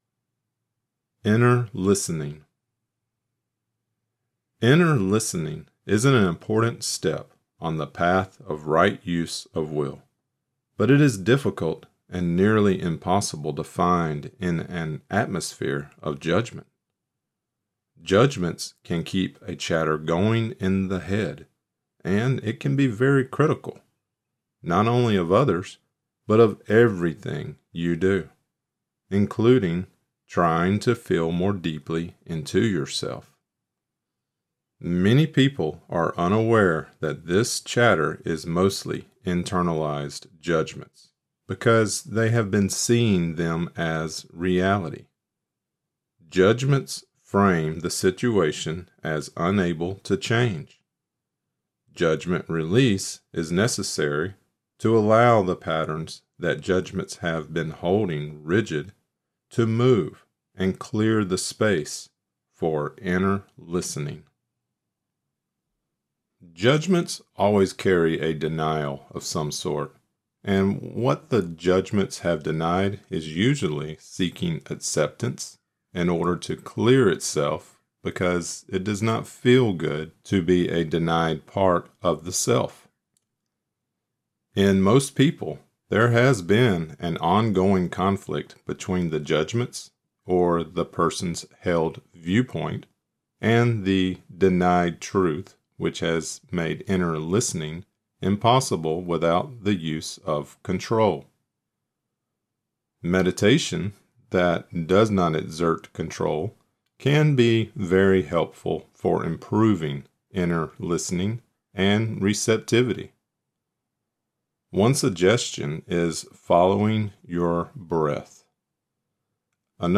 Lecture Created Transcript Blockchain Inner Listening 09/10/2025 Inner Listening (audio only) 09/10/2025 Watch lecture: View 2025 Lectures View All Lectures